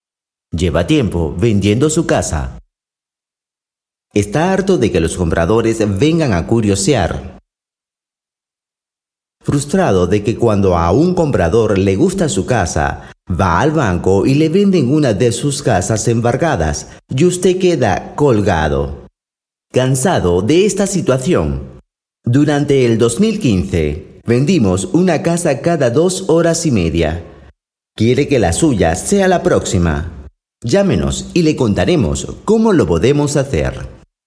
Grupo Lugo -Voz off
Voz para video corporativo,